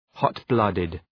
{‘hɒt,blʌdıd}